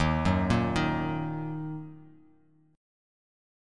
D#m Chord
Listen to D#m strummed